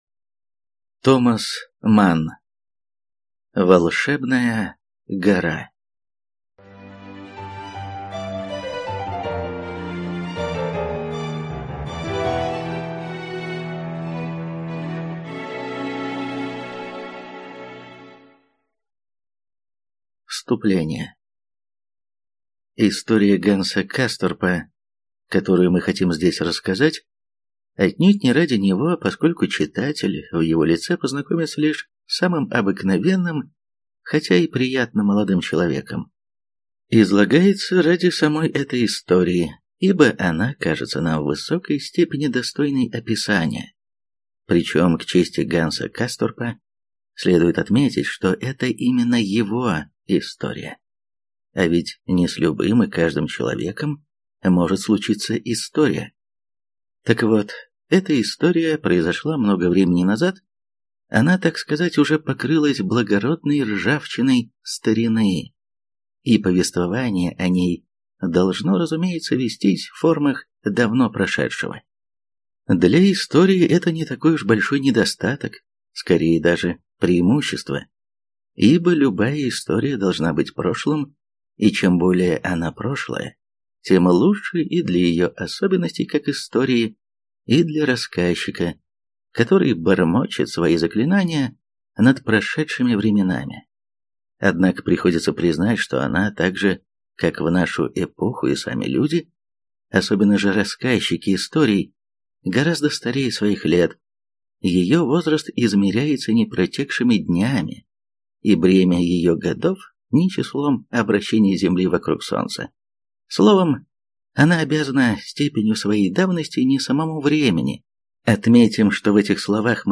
Аудиокнига Волшебная гора. Часть 1 | Библиотека аудиокниг